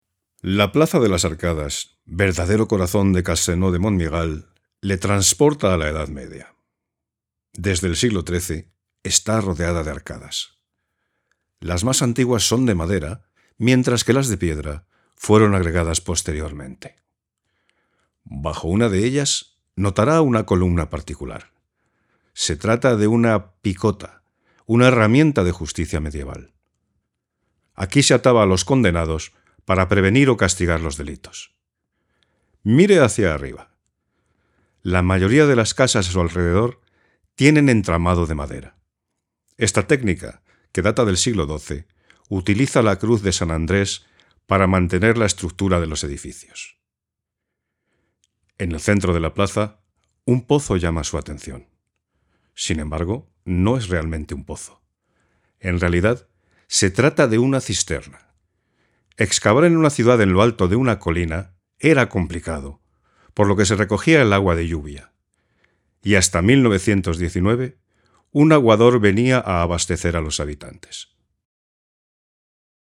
A mature and authoritative, reliable and cordial voice.
Campaña publicitaria Castelnau du Montmiral (sample)
Castelanian
Middle Aged